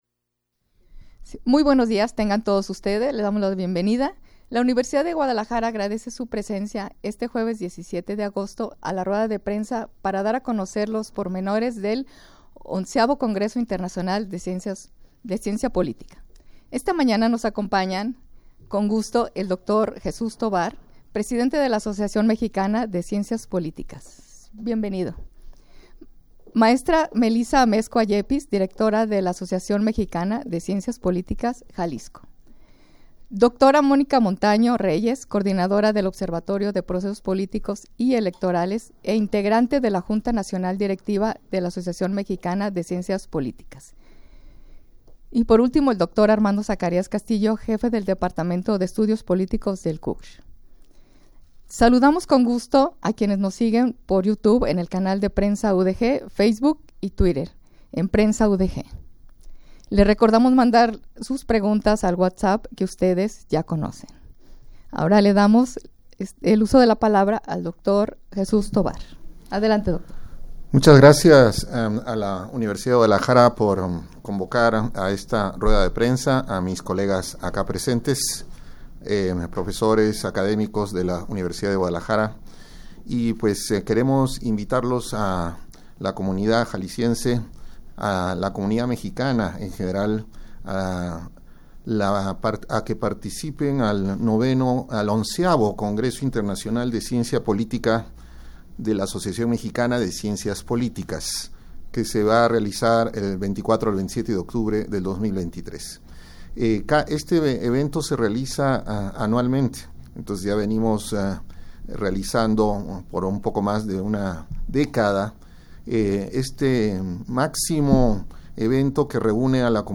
Audio de la Rueda de Prensa
rueda-de-prensa-para-dar-a-conocer-los-pormenores-del-xi-congreso-internacional-de-ciencia-politica.mp3